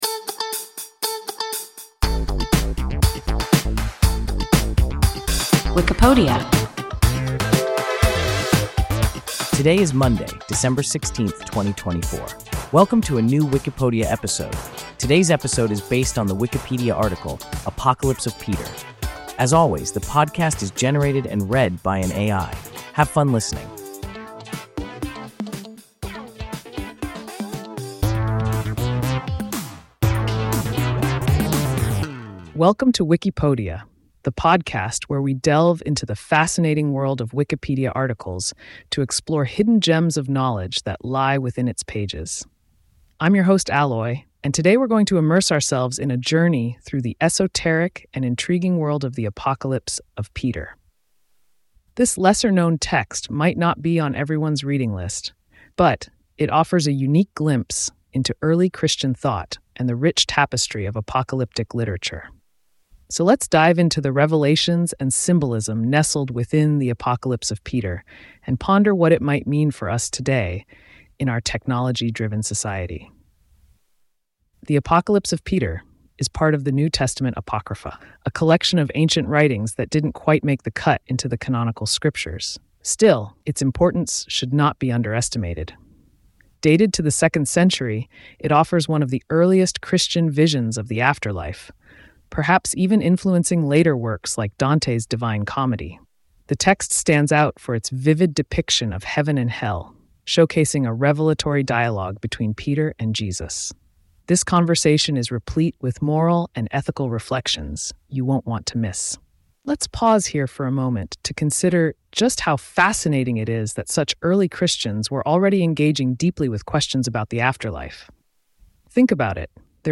Apocalypse of Peter – WIKIPODIA – ein KI Podcast